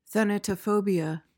PRONUNCIATION: (than-uh-tuh-FO-bee-uh) MEANING: noun : An excessive or irrational fear of death.